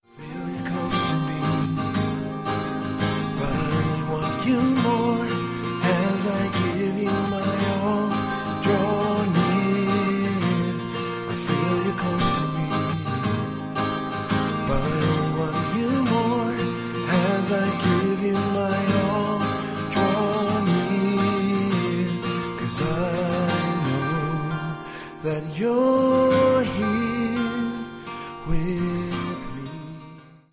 live worship
• Sachgebiet: Praise & Worship